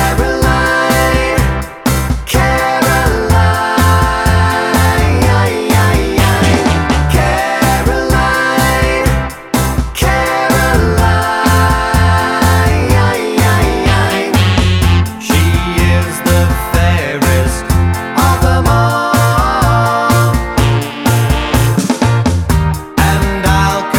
Pop (1960s)